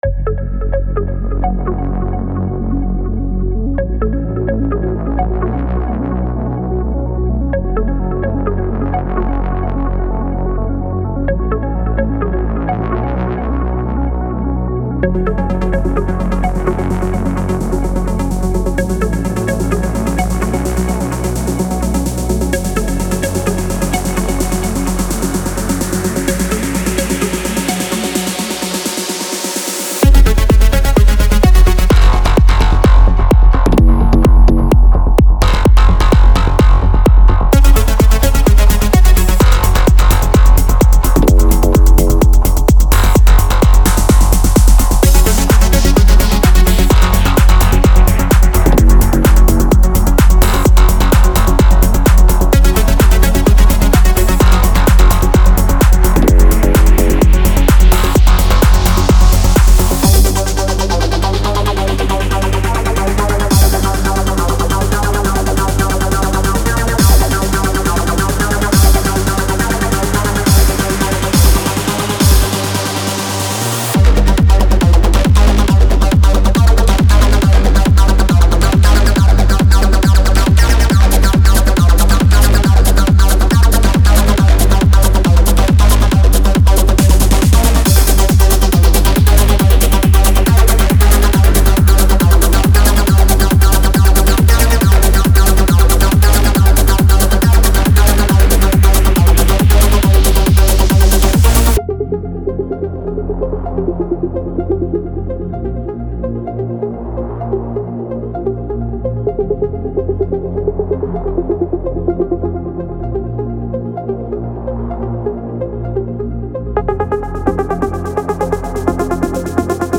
Melodic Techno Techno
• Recorded in 128-138 Bpm & Key labelled